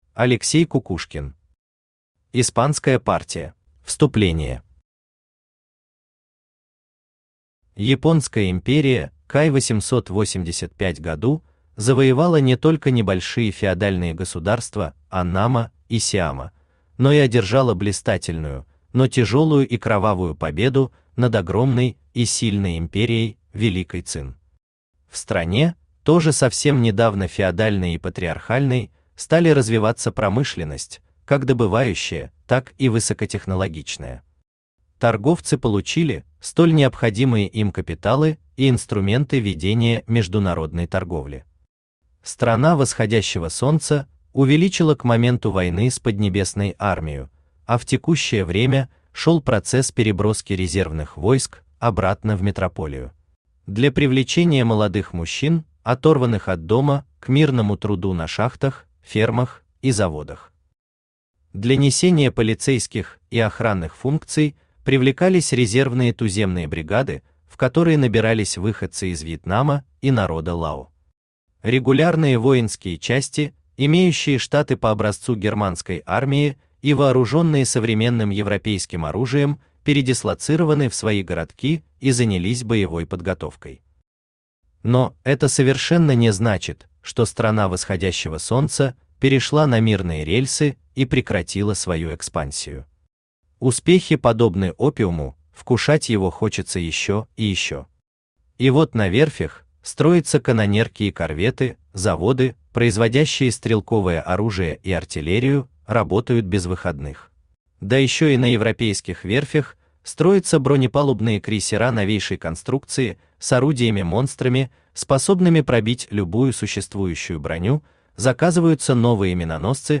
Аудиокнига Испанская партия | Библиотека аудиокниг
Aудиокнига Испанская партия Автор Алексей Николаевич Кукушкин Читает аудиокнигу Авточтец ЛитРес.